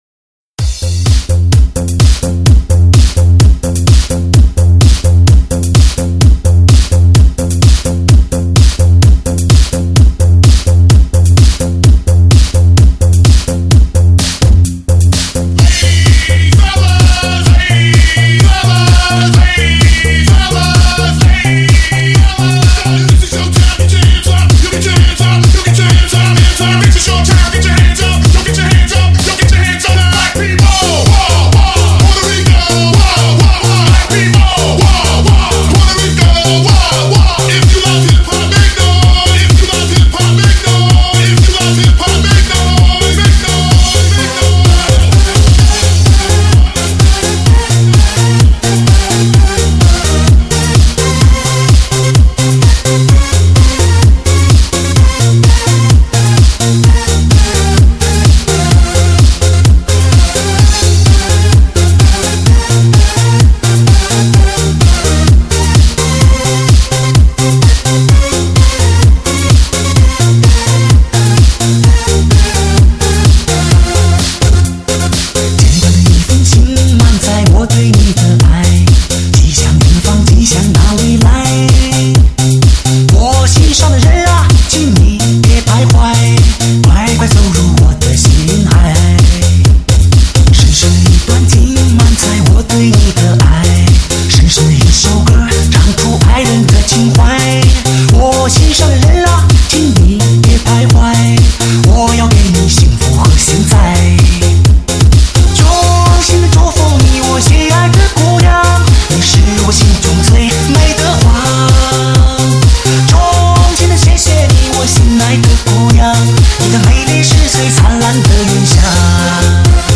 专辑格式：DTS-CD-5.1声道
红遍全国大小迪厅的至尊中文嗨曲，最HI劲歌金曲触动你每一根神经让你无限燃烧。